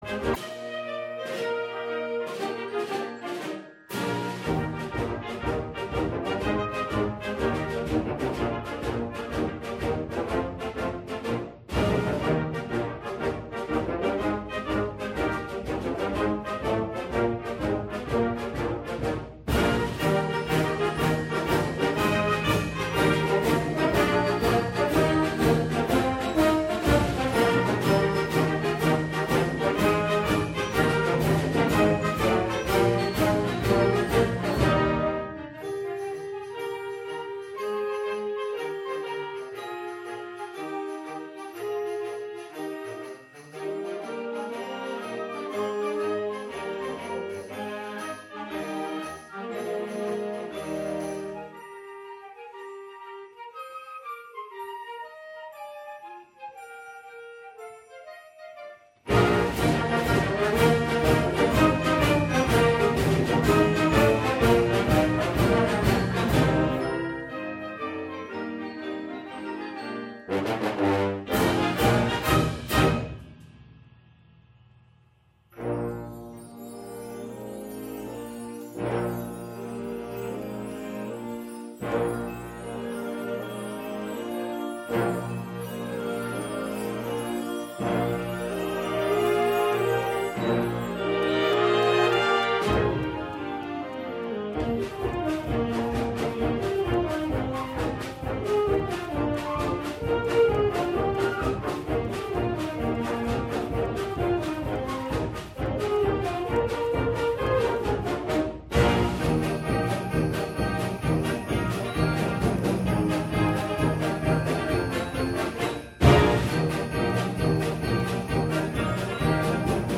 A collection of two beloved winter songs
begins with the lively 6/8